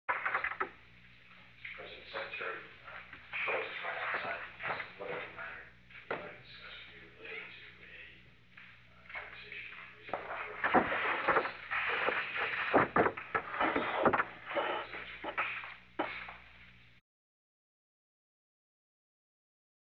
Secret White House Tapes
Location: Oval Office